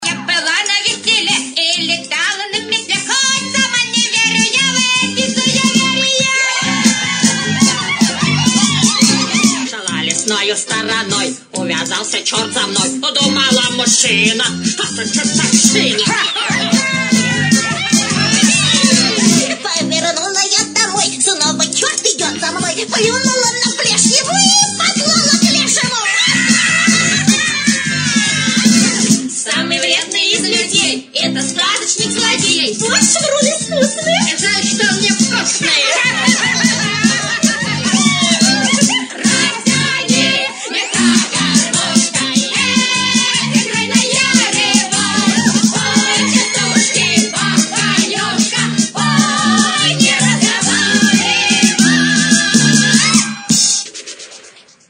Рингтоны » Саундтреки